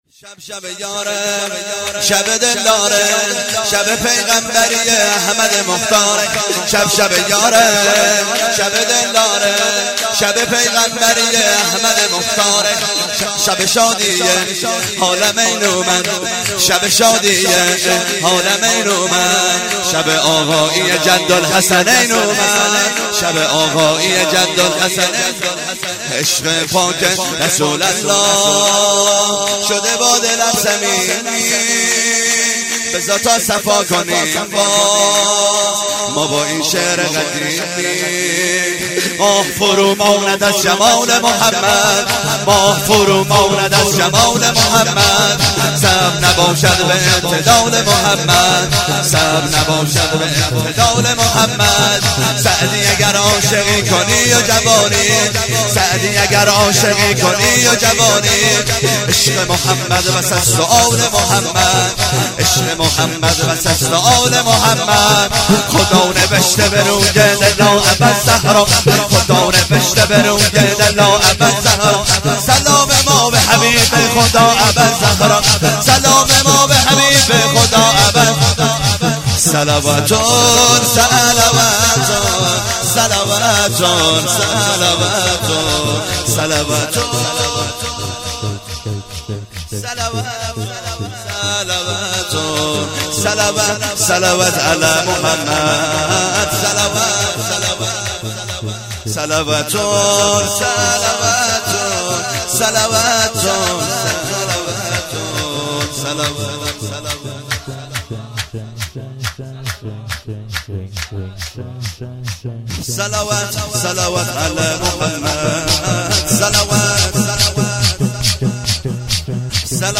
شور - شب شبِ یاره شب دلداره
جشن مبعث